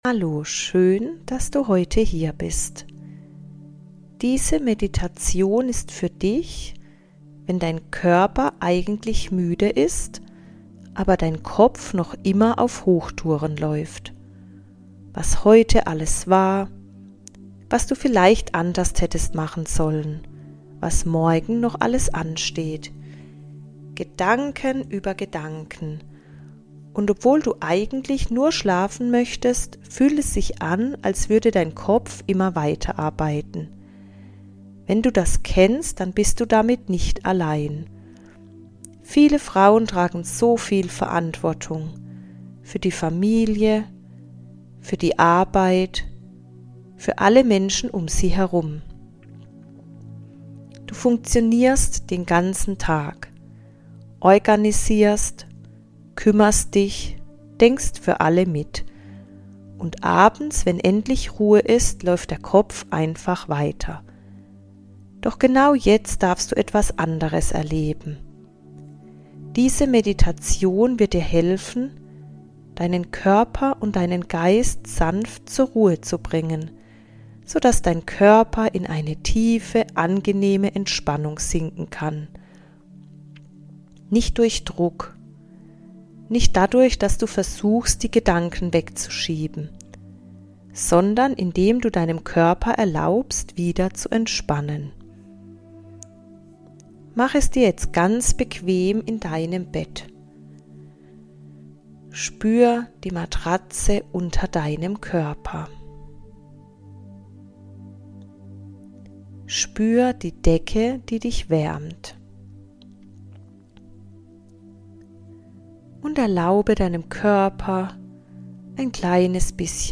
Geführte Einschlafmeditation ~ Gefühlsreise Podcast